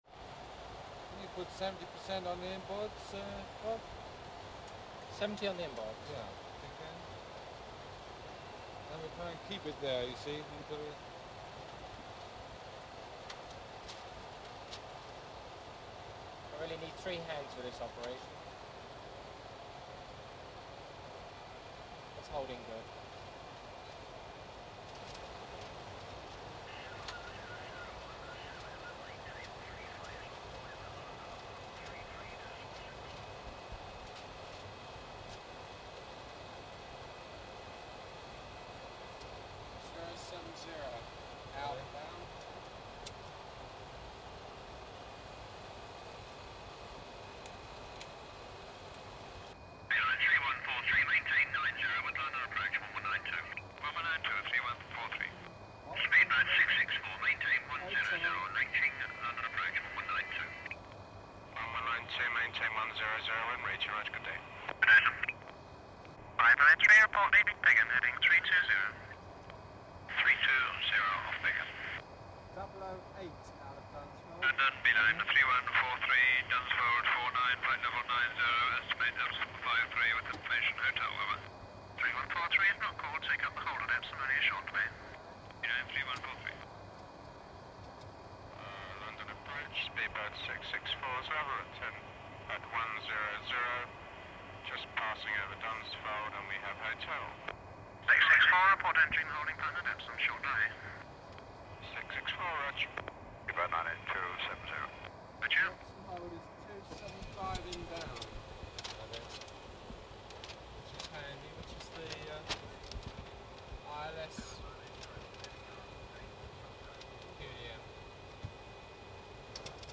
Boeing 707 cockpit, approaching london, radio & altitude adjustments